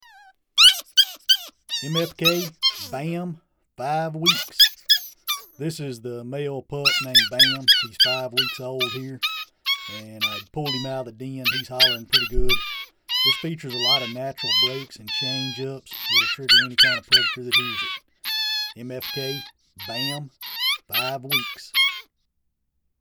Recorded with the best professional grade audio equipment MFK strives to produce the highest
The Big Difference- Our one-of-a-kind live coyote library naturally recorded at extremely close
range from our very own hand raised, free range coyotes sets MFK apart from all other libraries. Making